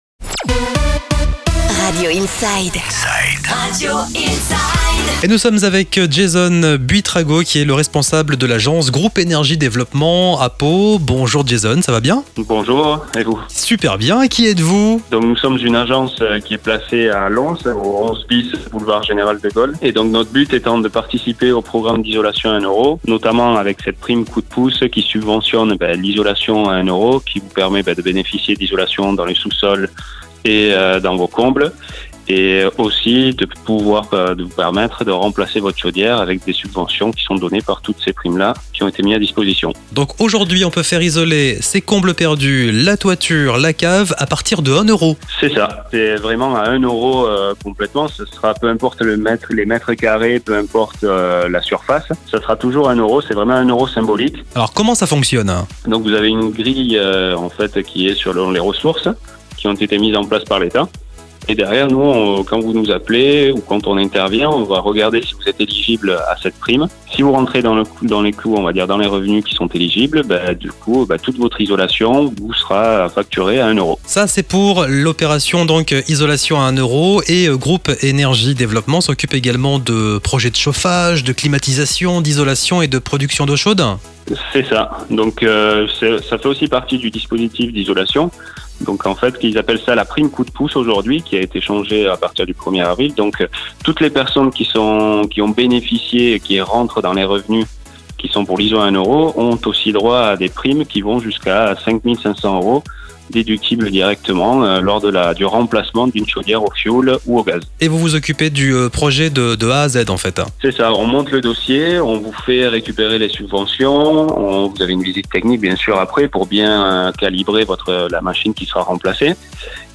Les offres d’emploi dans votre région, les sorties cinéma dans votre ville ou la vie des associations locales, etc. RadioProd produit ces chroniques pour vous! Chaque jour, nos équipes réalisent des inserts téléphoniques avec les acteurs locaux de votre territoire et vous livrent des chroniques en PAD pour le simple prix d’un abonnement mensuel.